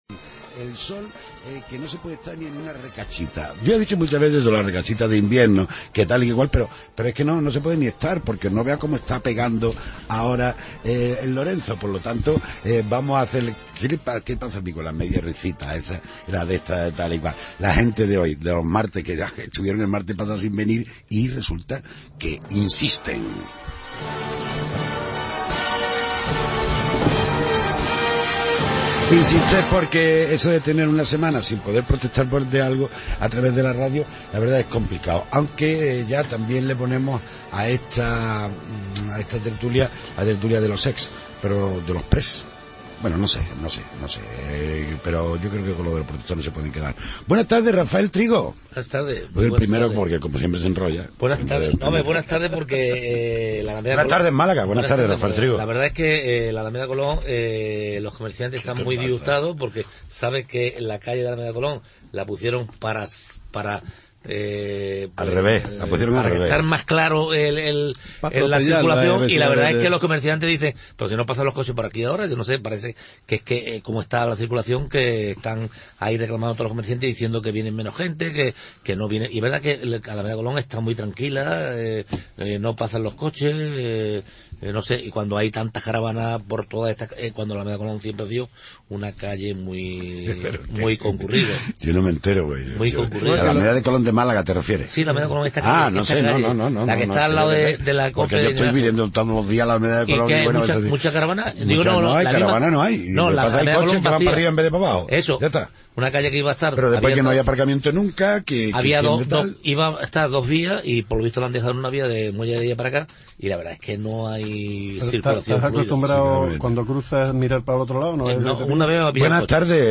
AUDIO: Tertulia de los temas que interesan a Málaga y los Malagueños.